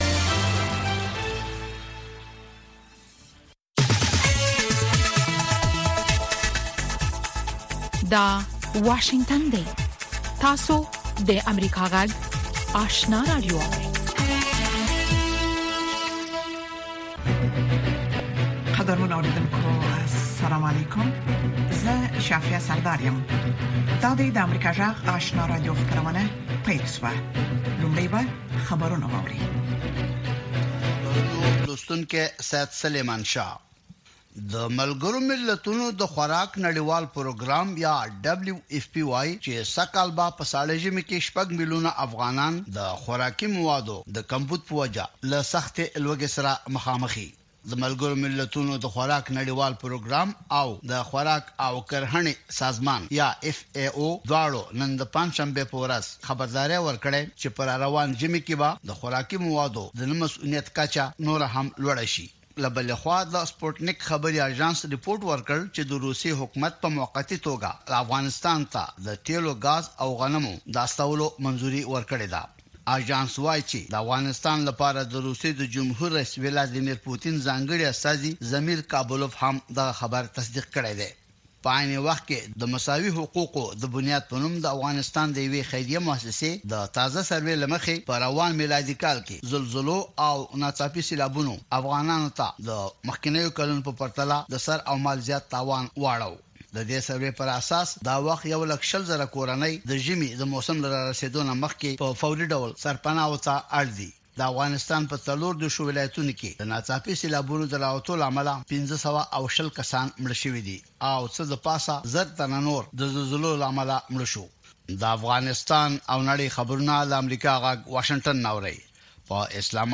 ماښامنۍ خبري خپرونه